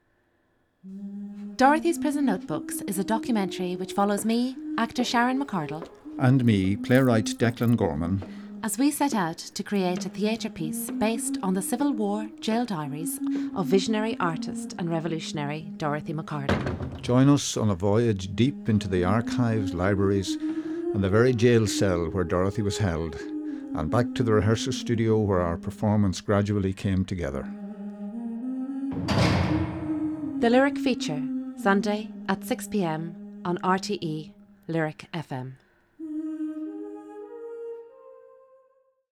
Short promo for radio documentary aired on RTÉ